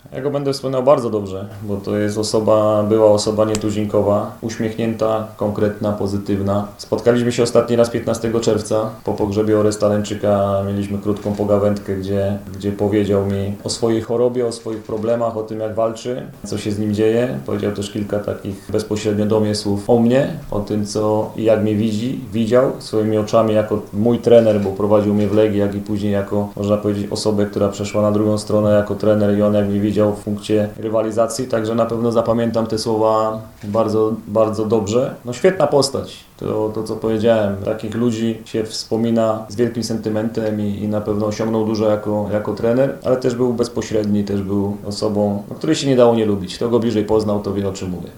Postać tę wspominał na pomeczowej konferencji Jacek Magiera.